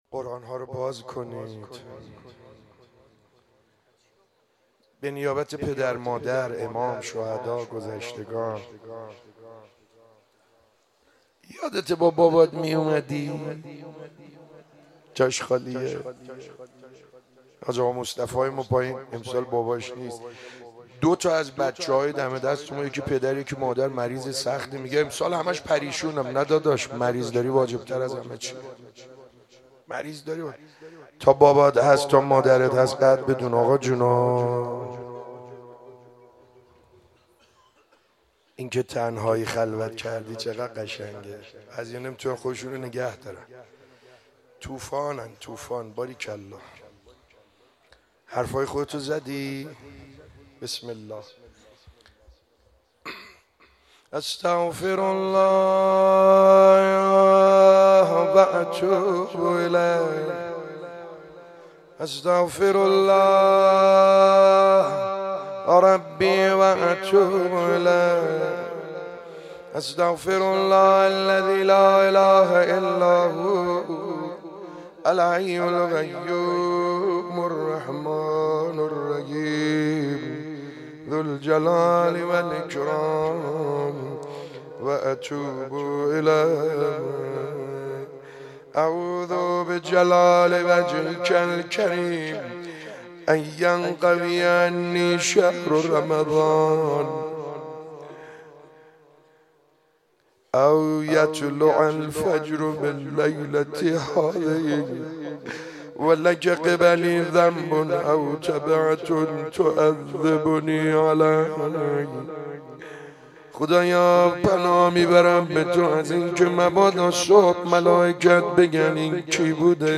شب 19 ماه مبارک رمضان 95(قدر)_قرآن به سر